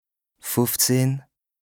2APRESTA_OLCA_LEXIQUE_INDISPENSABLE_BAS_RHIN_96_0.mp3